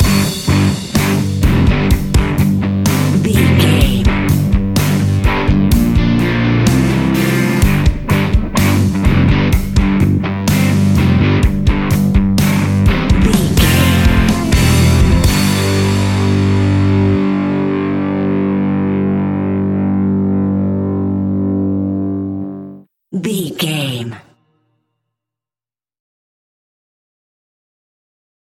Ionian/Major
energetic
driving
happy
bright
electric guitar
bass guitar
drums
hard rock
distortion
rock instrumentals
heavy drums
distorted guitars
hammond organ